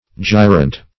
gyrant - definition of gyrant - synonyms, pronunciation, spelling from Free Dictionary Search Result for " gyrant" : The Collaborative International Dictionary of English v.0.48: Gyrant \Gy"rant\ (j[imac]"rant), a. Gyrating.